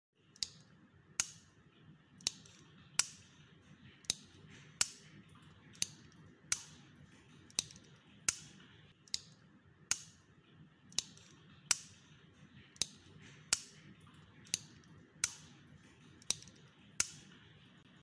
Tiếng Bấm bút bi kêu tạch tạch
Thể loại: Tiếng đồ vật
Description: Hiệu ứng âm thanh tiếng bấm nút cây bút bi kêu cật cật, tiếng bấm viết bi kêu lách cách... Âm thanh thực kêu liên tục này cho cảm giác dễ chịu có thể dùng để thư giãn, dễ ngủ hoặc edit video...
tieng-bam-but-bi-keu-tach-tach-www_tiengdong_com.mp3